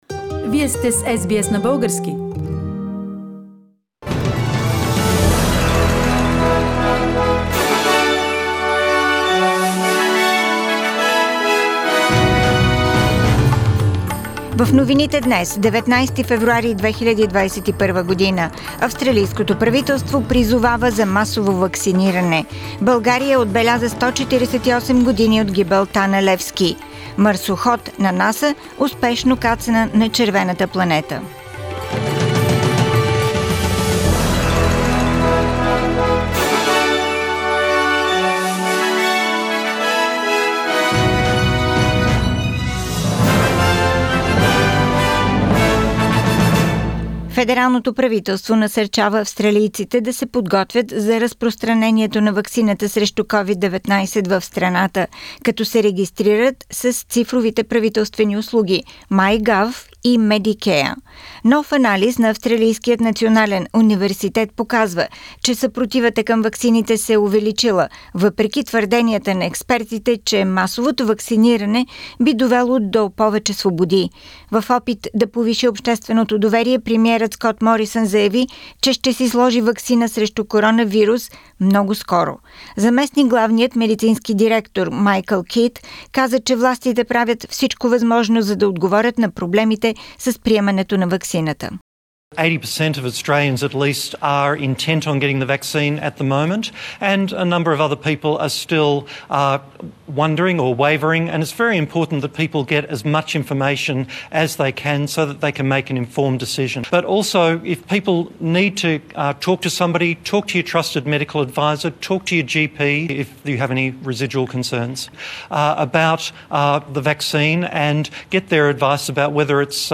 Weekly Bulgarian News – 19th February 2020